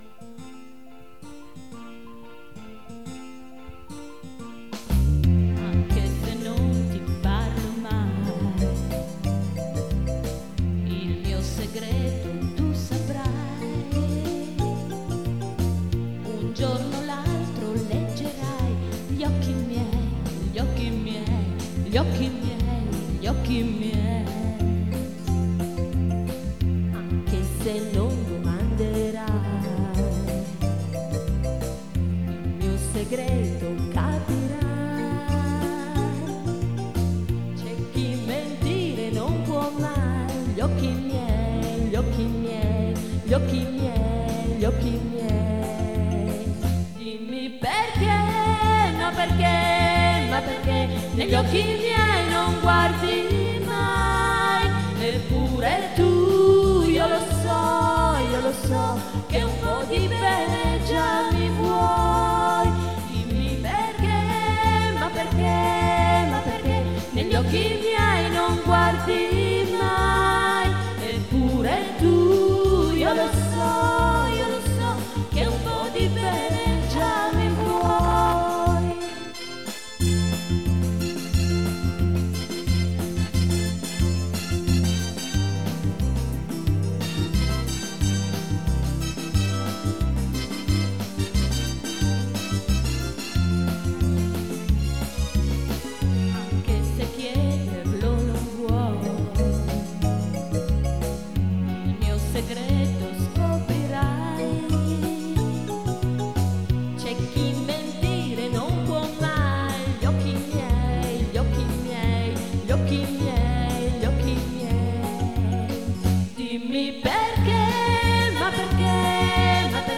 Il gruppo č composto da due voci maschili e sei femminili
Vi proponiamo ora l'ascolto di alcuni tra gli svariati brani proposti dal gruppo durante le serate, tutti cantati dal vivo.